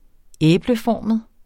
Udtale [ -ˌfɒˀməð ]